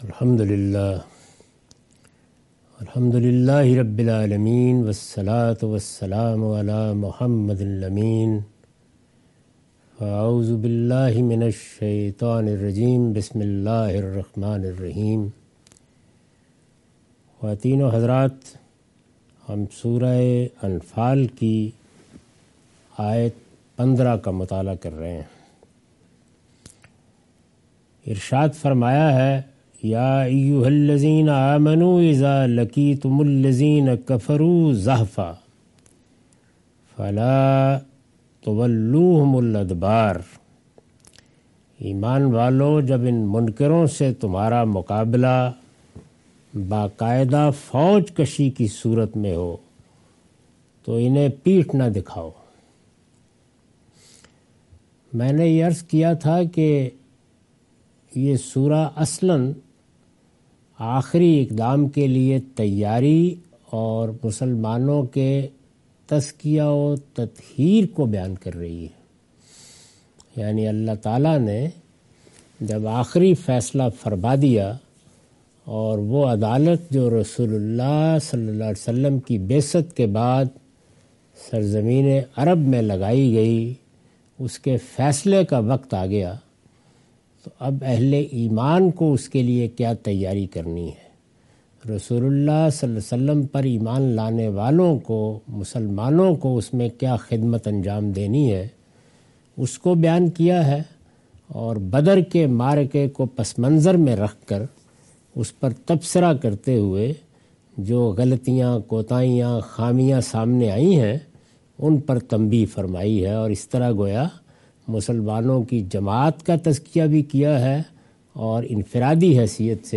Surah Al-Anfal - A lecture of Tafseer-ul-Quran – Al-Bayan by Javed Ahmad Ghamidi. Commentary and explanation of verses 15-19.